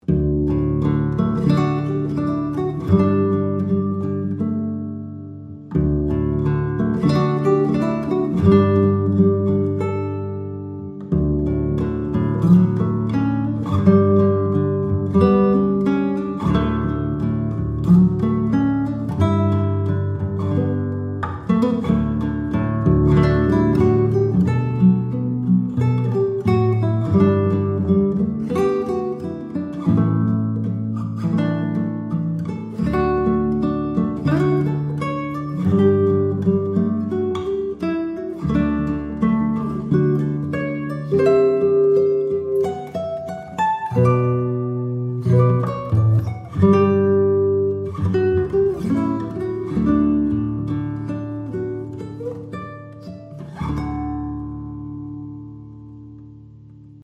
INSTRUMENTAL SAMPLES